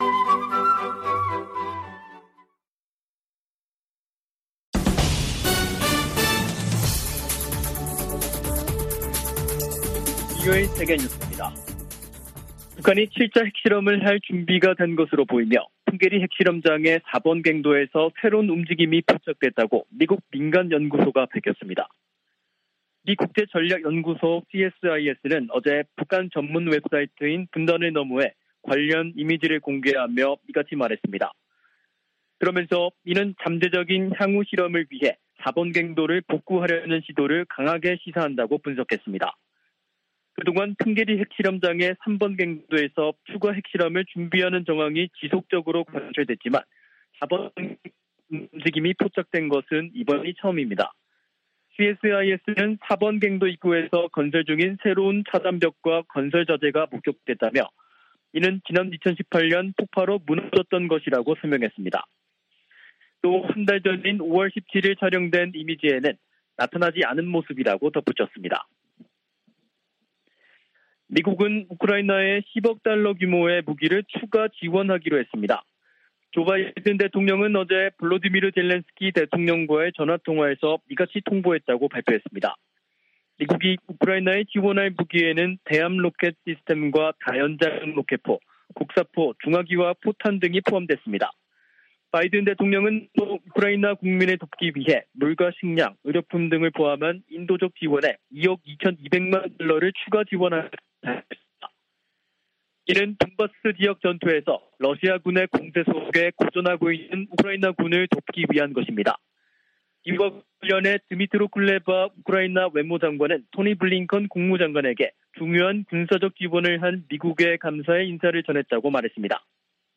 VOA 한국어 간판 뉴스 프로그램 '뉴스 투데이', 2022년 6월 16일 2부 방송입니다. 미 하원 세출위원회 국방 소위원회가 북한 관련 지출을 금지하는 내용을 담은 2023 회계연도 예산안을 승인했습니다. 북한 풍계리 핵실험장 4번 갱도에서 새로운 움직임이 포착됐다고 미국의 민간연구소가 밝혔습니다. 북한이 지난해 핵무기 개발에 6억4천200만 달러를 썼다는 추산이 나왔습니다.